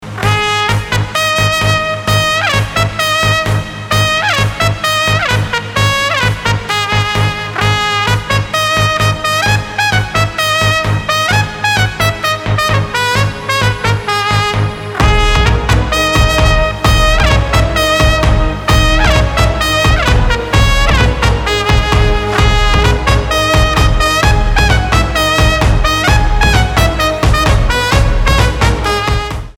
• Качество: 320, Stereo
зажигательные
без слов
progressive house
труба
Яркий рингтон без слов стиля progressive house